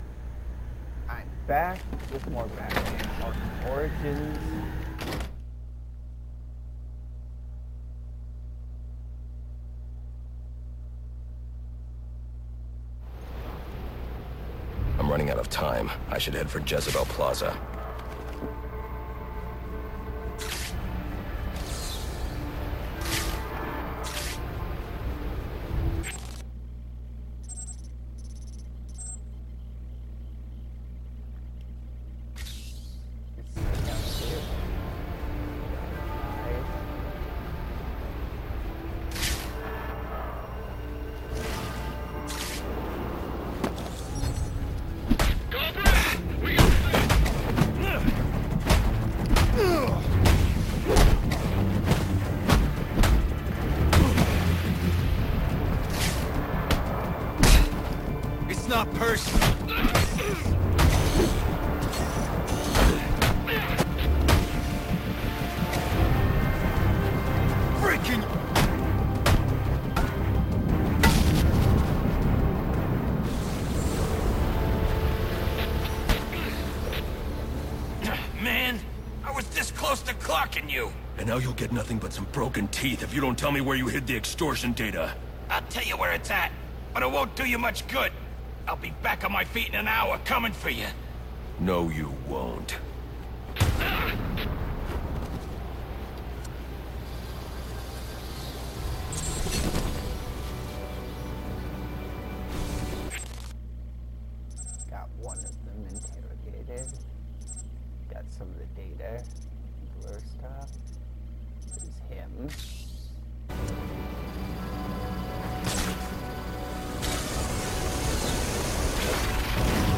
I play Batman Arkham Origins with commentary